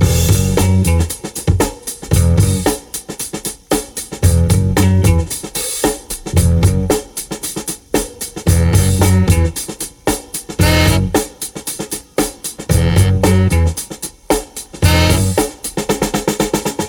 • 114 Bpm Fresh Drum Groove E Key.wav
Free breakbeat sample - kick tuned to the E note.
114-bpm-fresh-drum-groove-e-key-kwk.wav